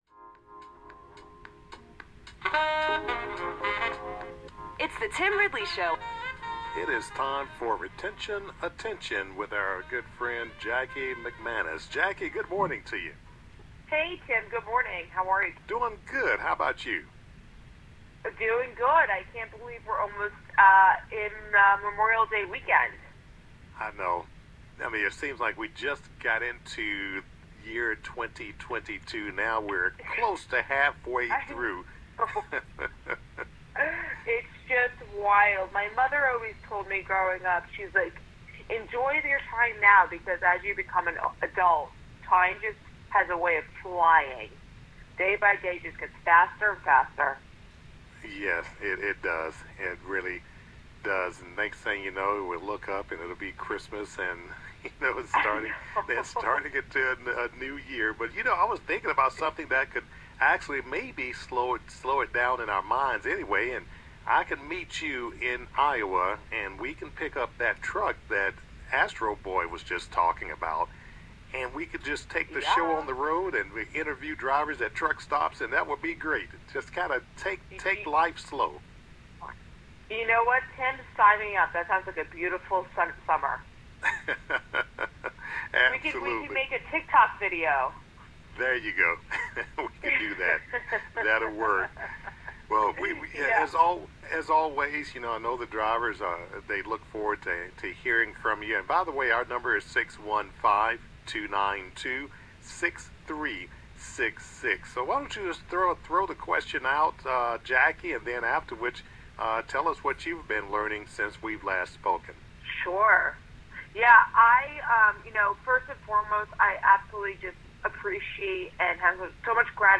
A Kentucky flatbedder who works for a smaller carrier calls in. He shares that he stays with his company because he feels that his dispatcher truly cares about his wellbeing; the company puts the driver first, and encourages drivers to take rests when needed.